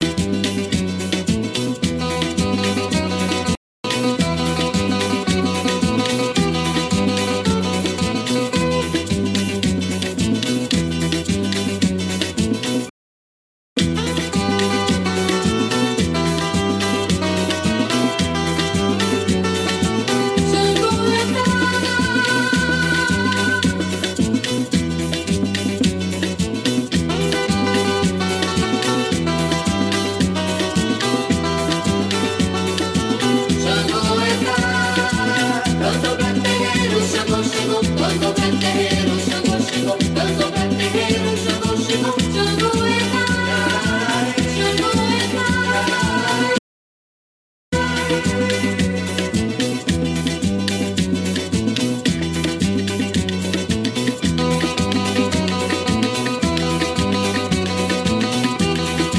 dancefloor monsters